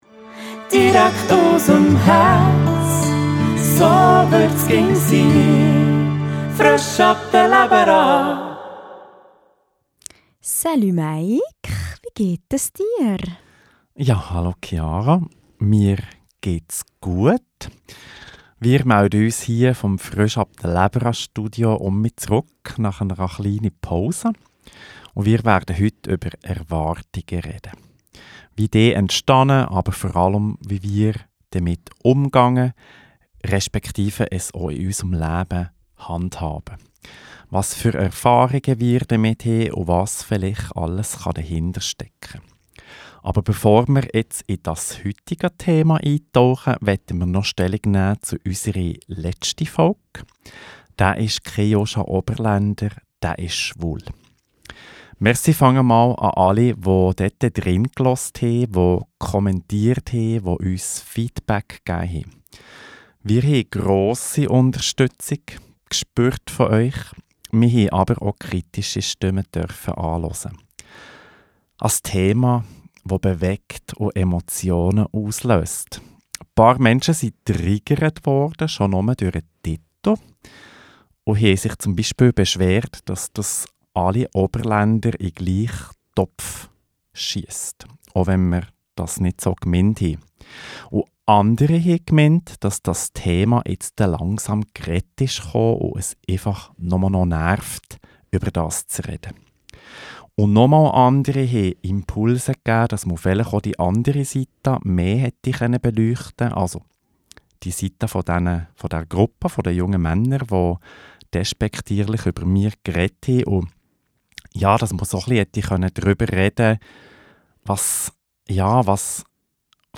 im Gspräch